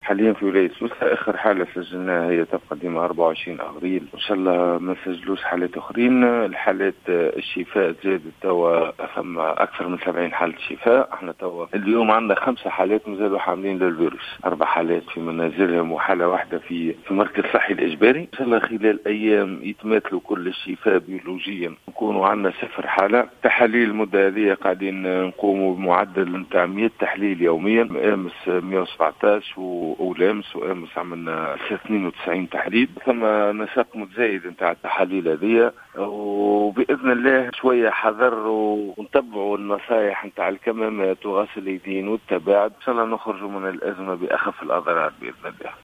أكد المدير الجهوي للصحة بسوسة، سامي الرقيق في تصريح اليوم لـ"الجوهرة أف أم" عدم تسجيل إصابات جديدة بفيروس كورونا منذ يوم 24 أفريل الماضي.